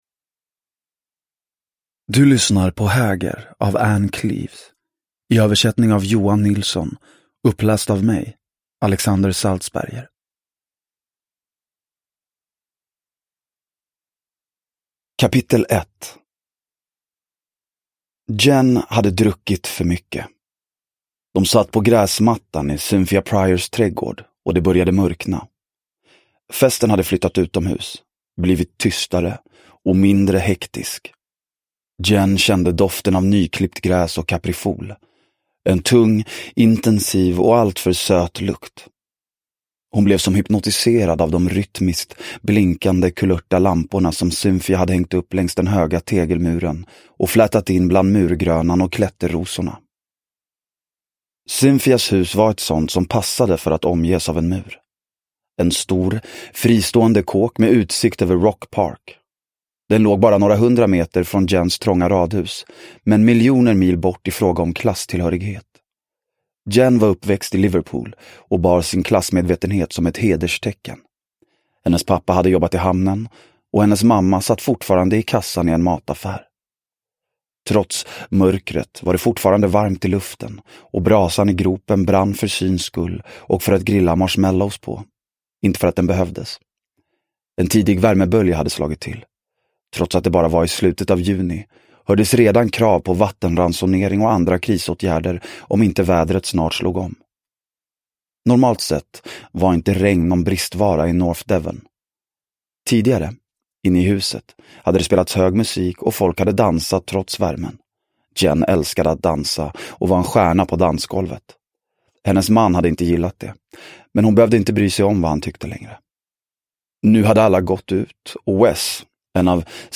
Häger – Ljudbok – Laddas ner